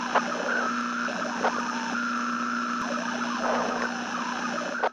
nerfs_psynoise4.ogg